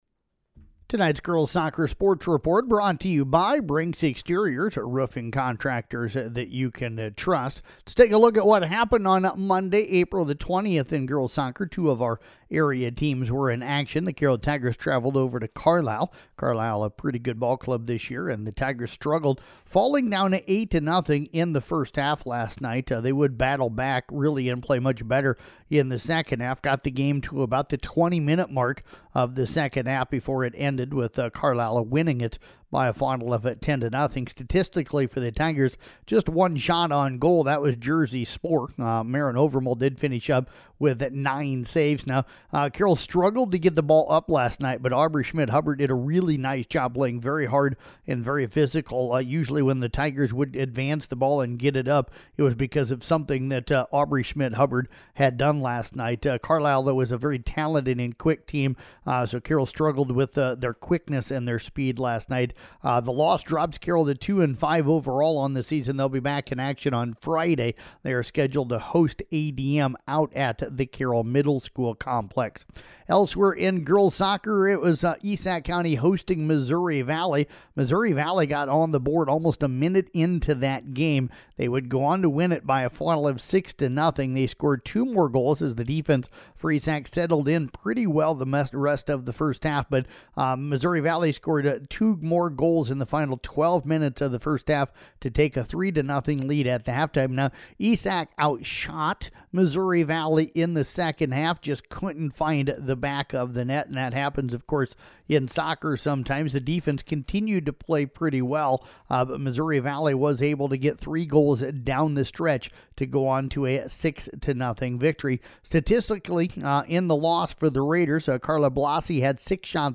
Below is an audio recap of Girls Soccer for Monday, April 20th
girls-soccer-recap-monday-april-20th.mp3